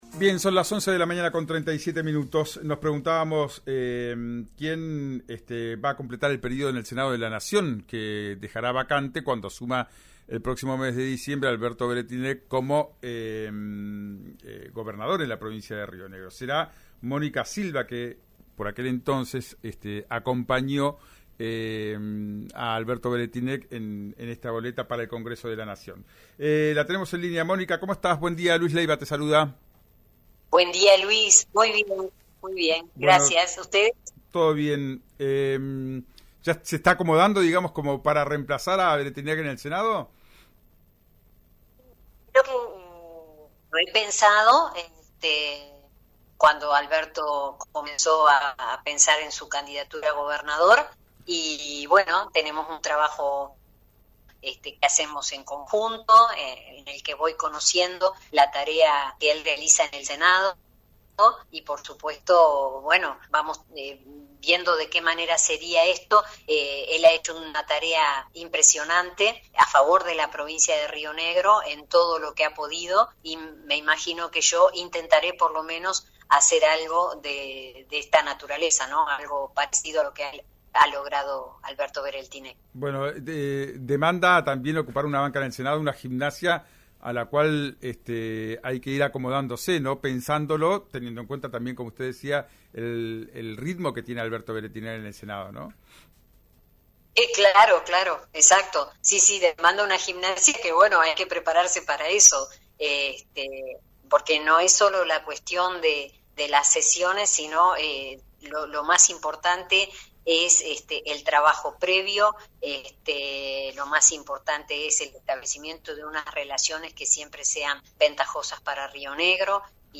En diálogo con RÍO NEGRO RADIO, Silva confirmó el reemplazo y aseguró que irá ajustando la transición hasta el 10 de diciembre y que se mantendrá en el cargo hasta 2025, año en que el senador Weretilneck dejaría la banca.
Escuchá a Mónica Silva, legisladora de JSRN y exministra de Educación, con RÍO NEGRO RADIO: